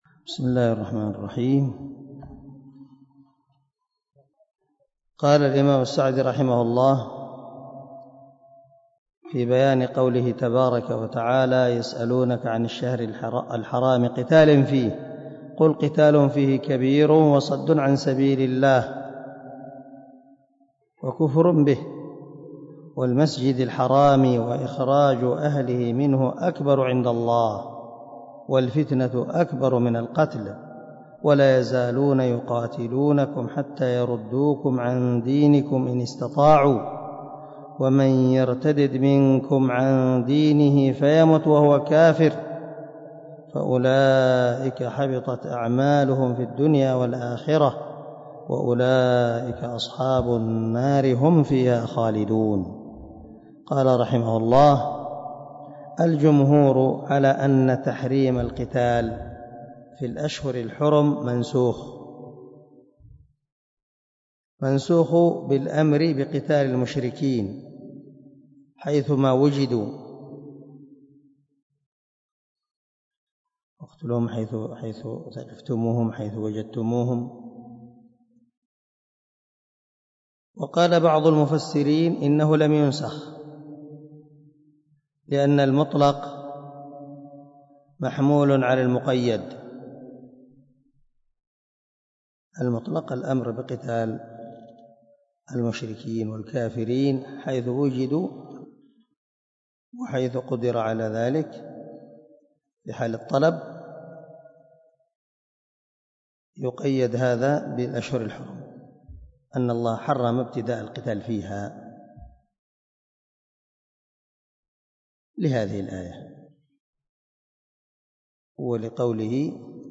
106الدرس 96 تابع تفسير آية ( 217 ) من سورة البقرة من تفسير القران الكريم مع قراءة لتفسير السعدي
دار الحديث- المَحاوِلة- الصبيحة.